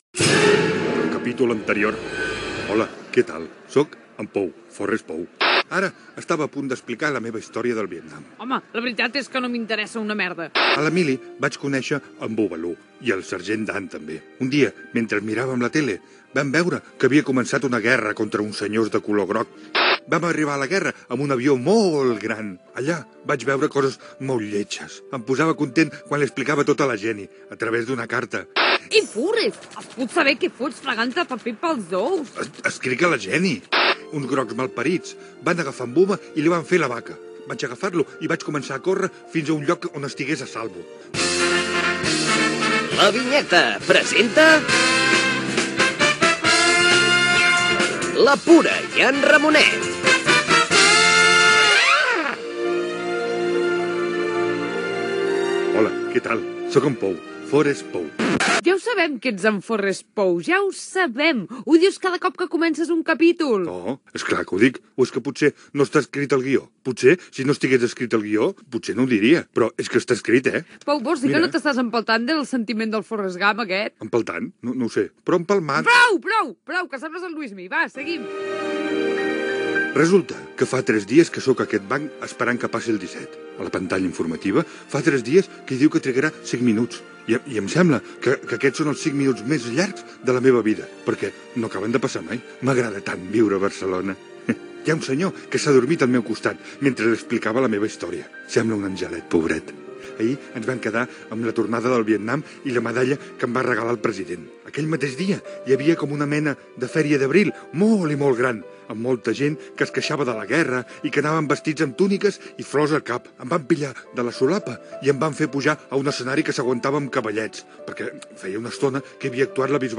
Ficció sonora